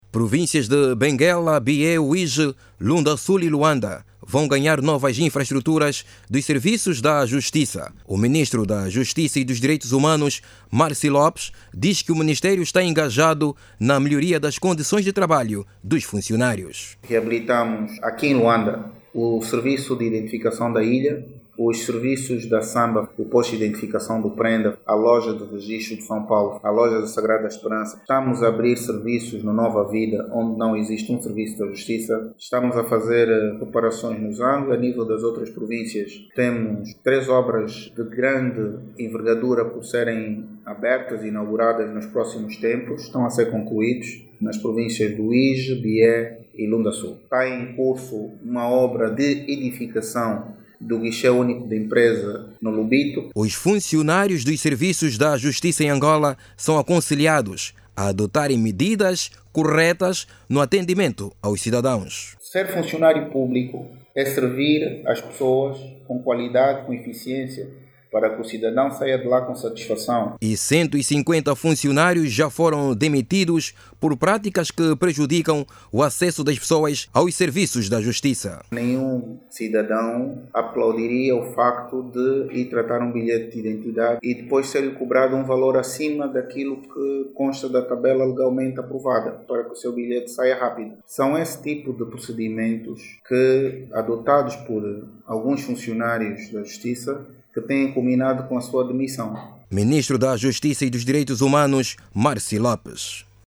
Saiba mais dados no áudio abaixo com o repórter